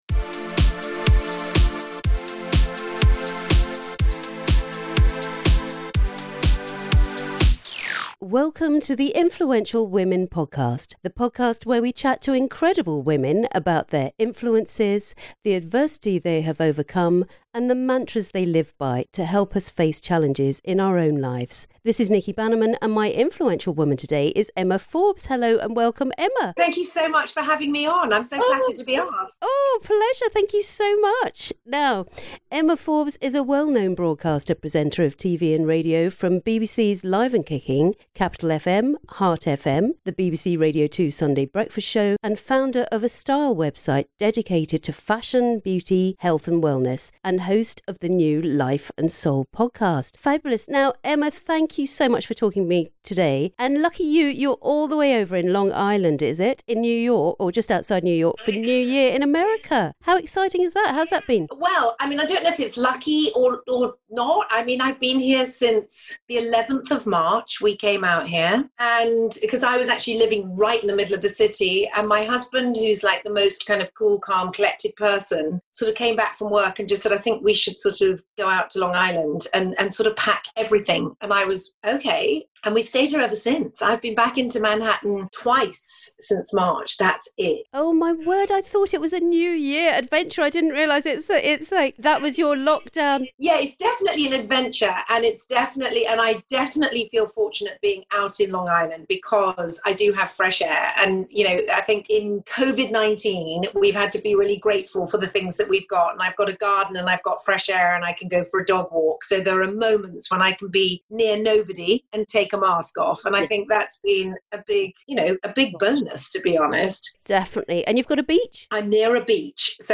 The Influential Women Podcast talks to Emma about her career and well travelled childhood and what it was like growing up with parents who were well known. We also chat to her about how the Coronavirus pandemic has affected her and how it’s made her appreciate life in a whole new light.